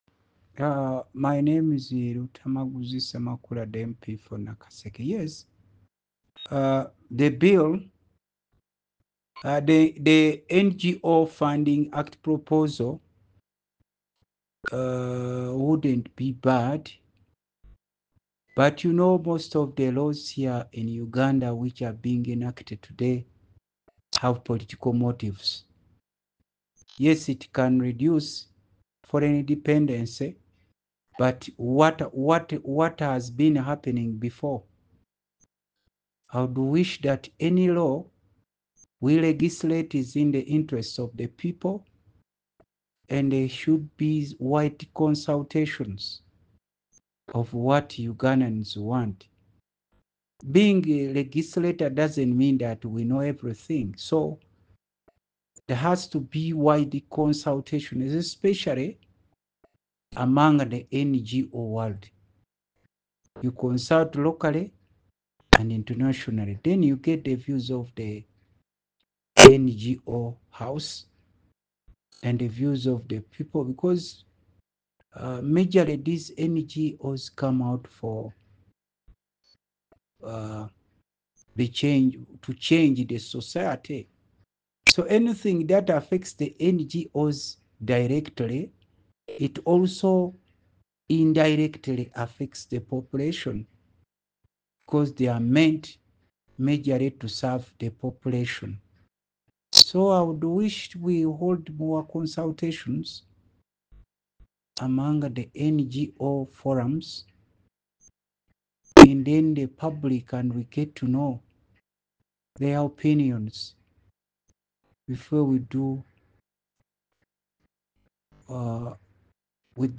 Listen: Nakaseke South MP Paulson Luttamaguzi Ssemakula.